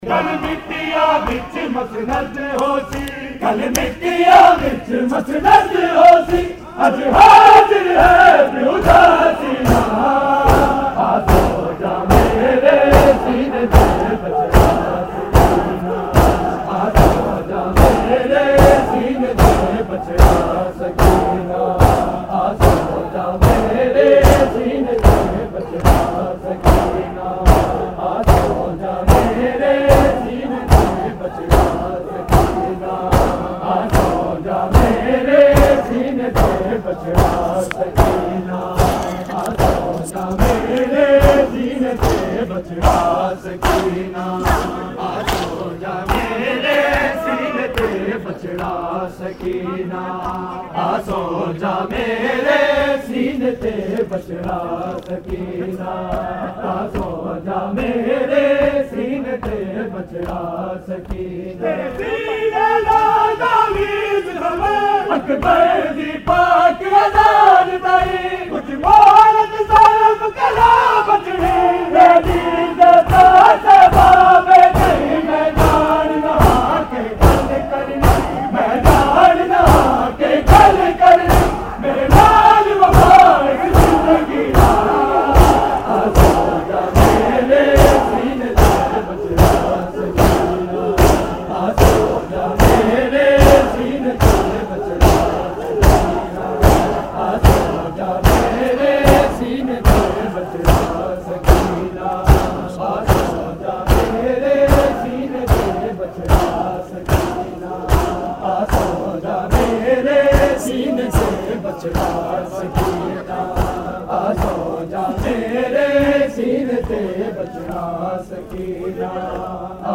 Recording Type: Live